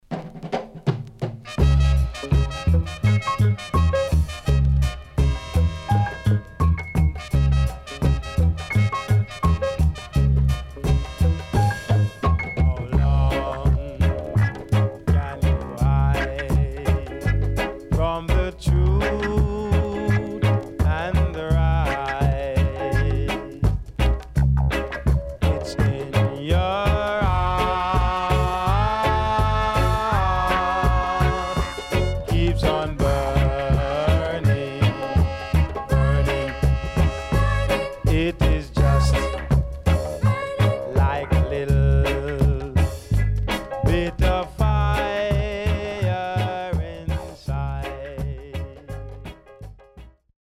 SIDE A:所々ノイズ入ります。